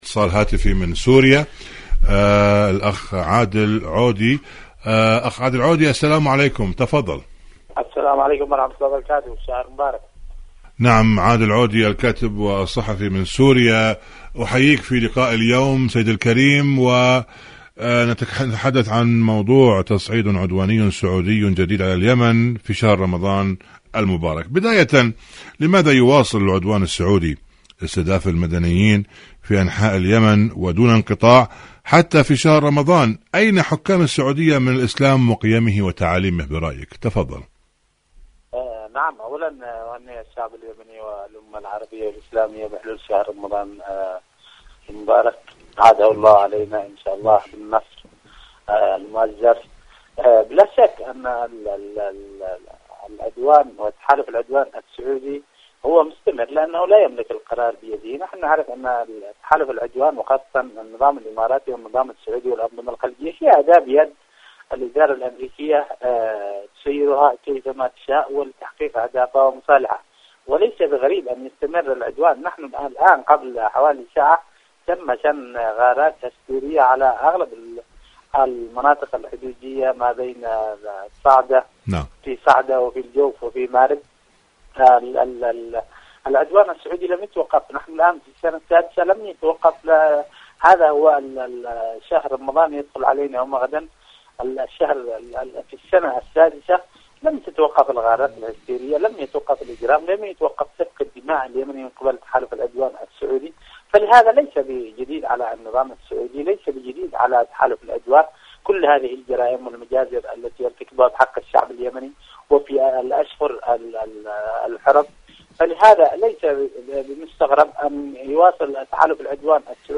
مقابلات مقابلات إذاعية برامج إذاعة طهران العربية برنامج اليمن التصدي والتحدي اليمن تصعيد عدواني جديد على اليمن شهر رمضان العدوان السعودي على اليمن شاركوا هذا الخبر مع أصدقائكم ذات صلة المرأة الحسينية وأدوارها..